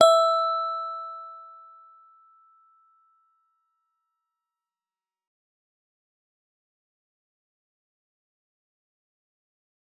G_Musicbox-E5-f.wav